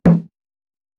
ストンプ